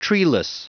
Prononciation du mot treeless en anglais (fichier audio)
Prononciation du mot : treeless